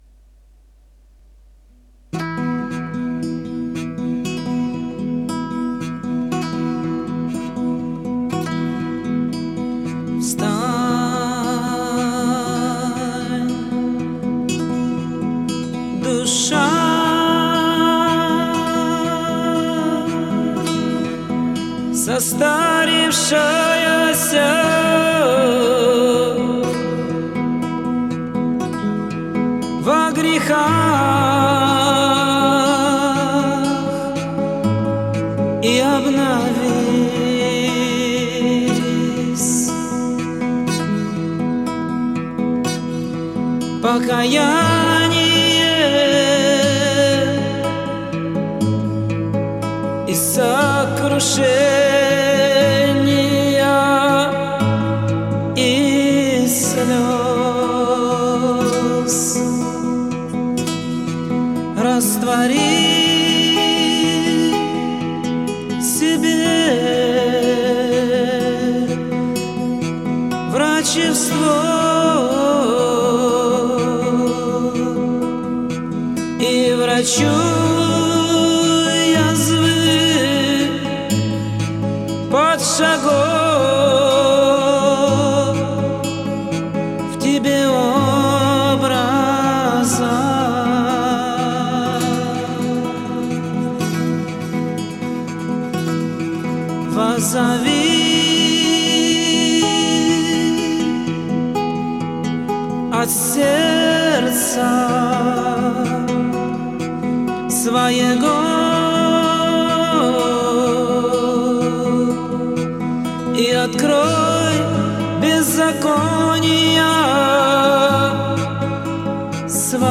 Духовные стихи, псалмы и песнопения, различных православных авторов 4-го и 19-го веков, исполняемые под гитару.